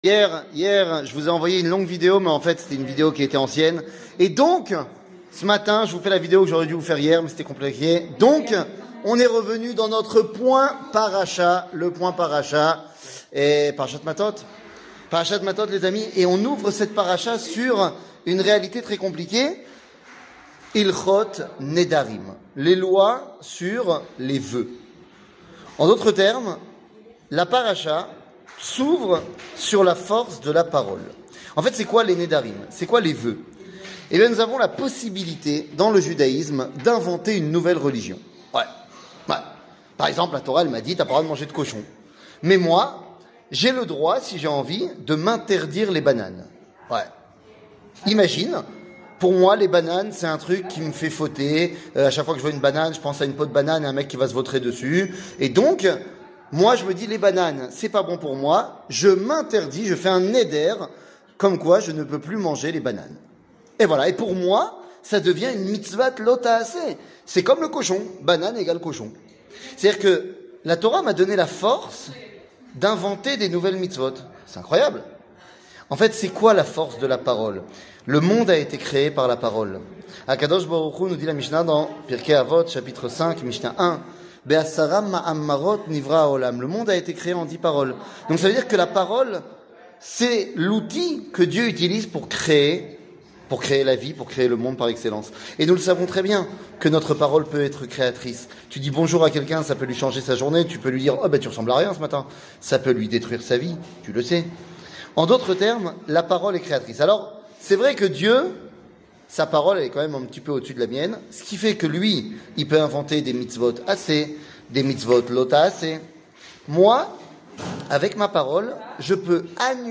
שיעור מ 22 יולי 2022
שיעורים קצרים